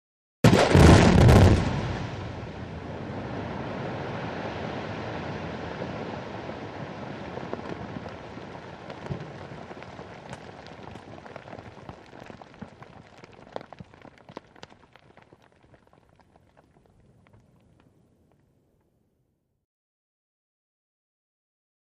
Dynamite Explosion; Dynamite Explosion / Whoosh / Falling Debris, Medium Perspective.